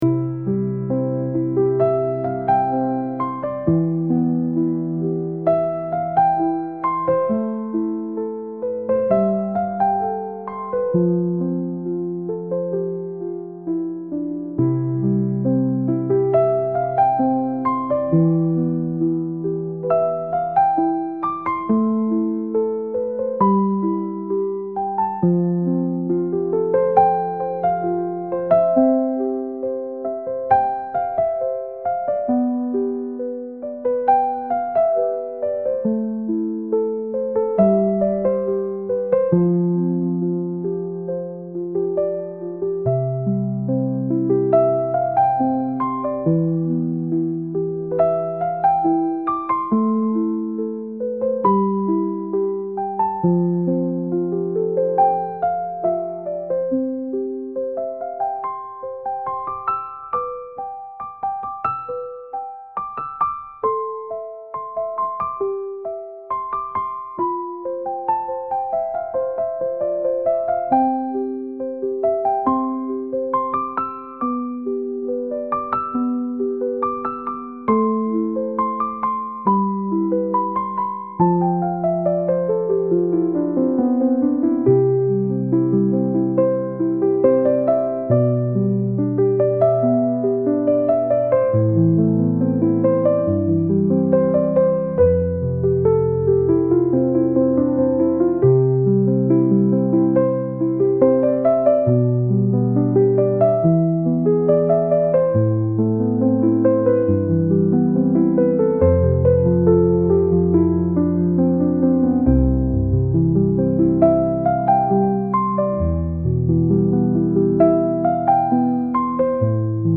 雨音入り癒しのlo-fi/cillミュージック